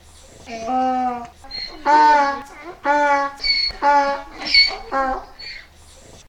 donkey-1.ogg